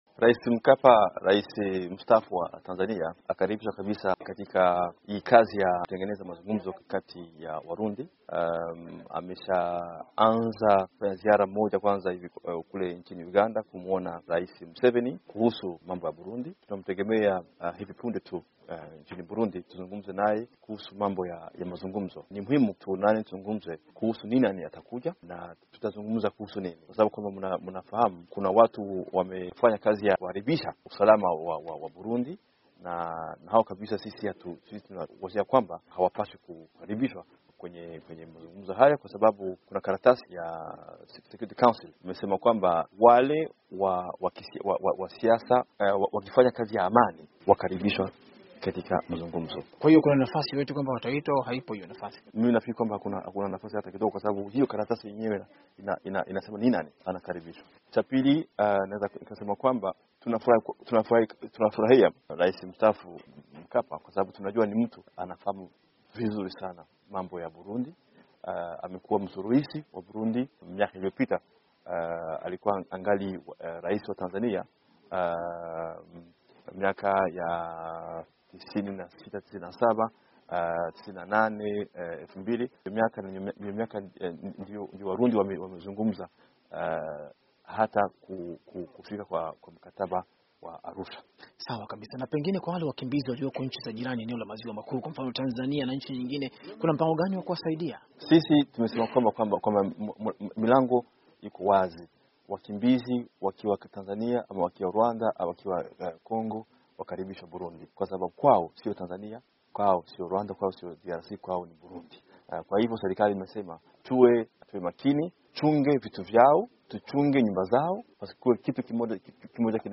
Mazungumzo na Alain Nyamitwe